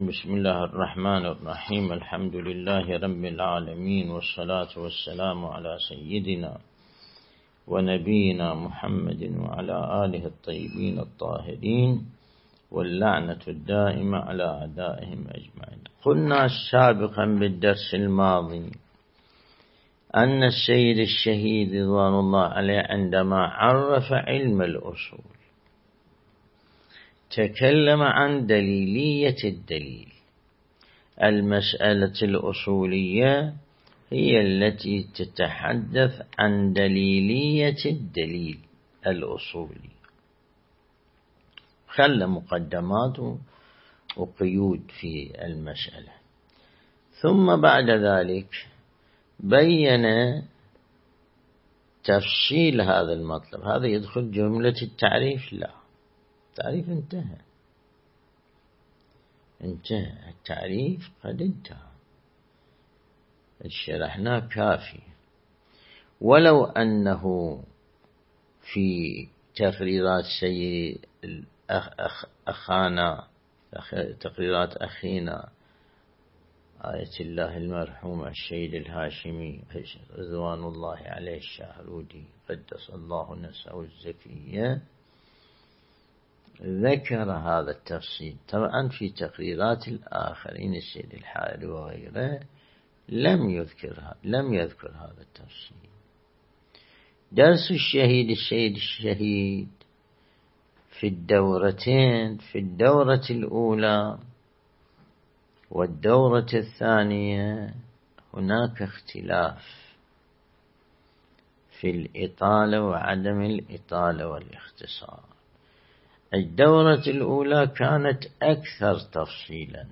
درس البحث الخارج الأصول (23)
النجف الأشرف